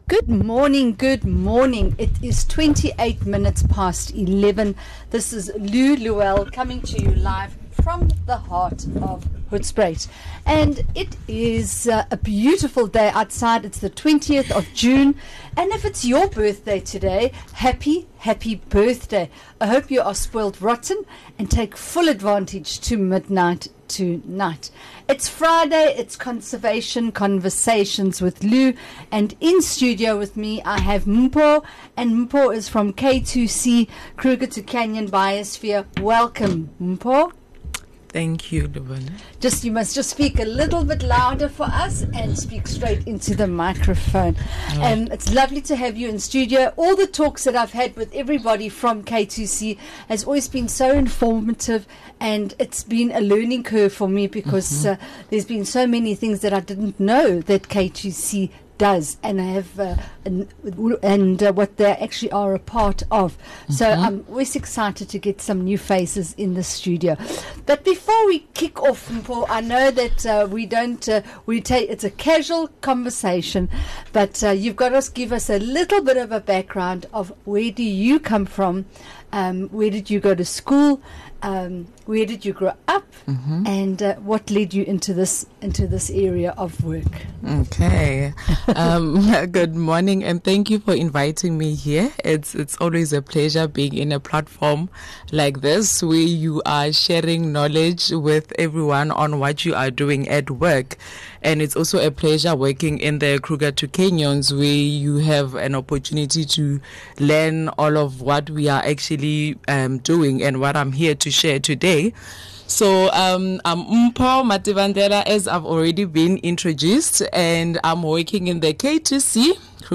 23 Jun Ongoing Interview with K2C Team